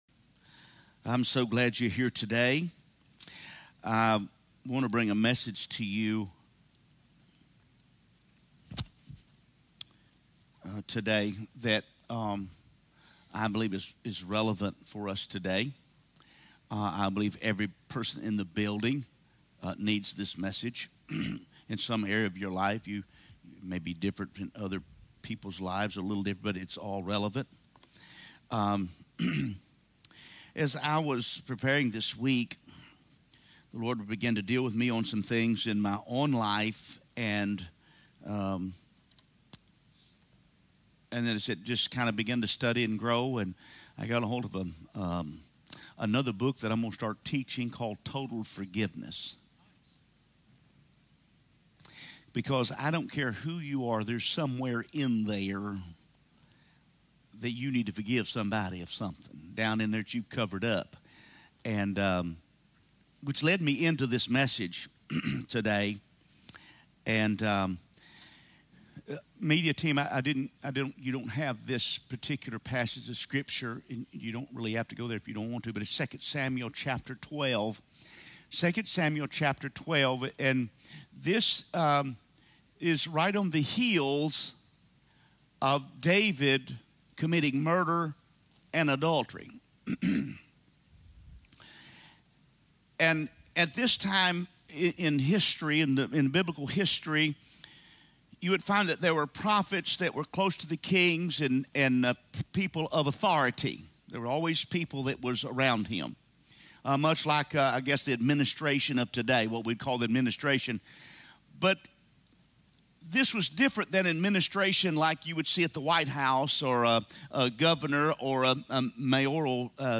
From Series: "Sunday Message"